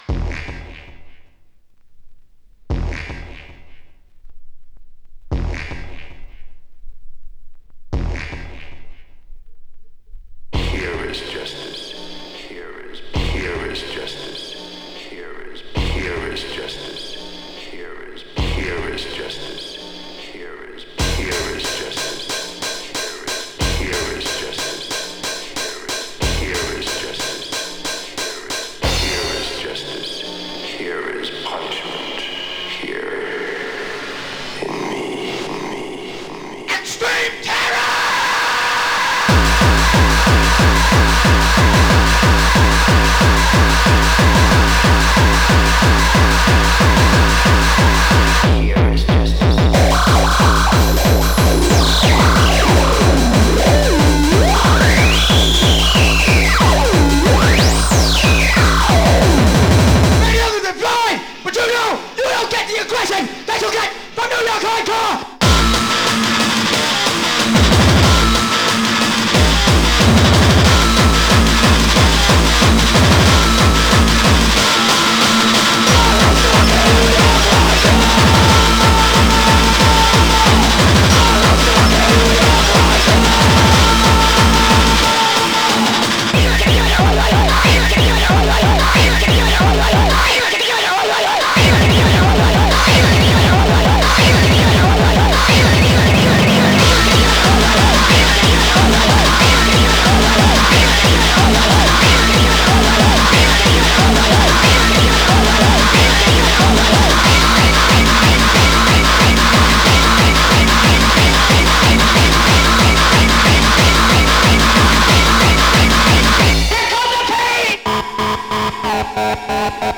Genre: Hardcore, Gabber.